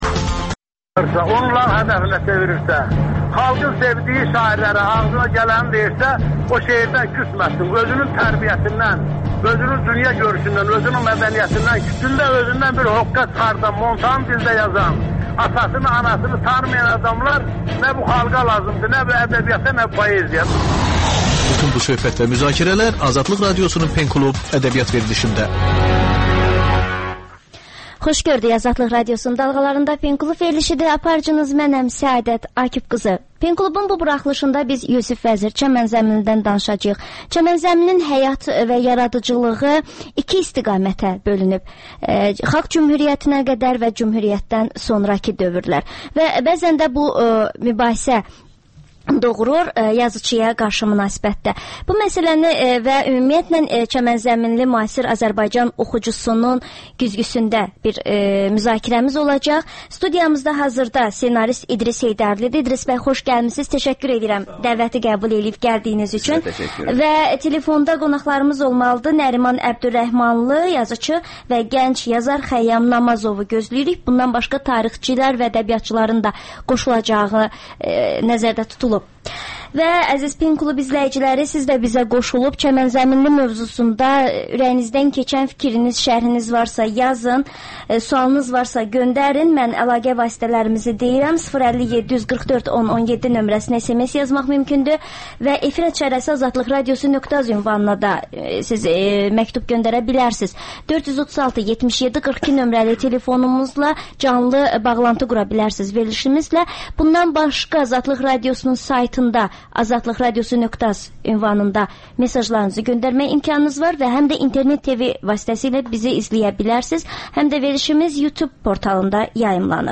AzadlıqRadiosunun müxbirləri canlı efirdə ölkədən və dünyadan hazırlanmış xəbərləri diqqətə yetirirlər.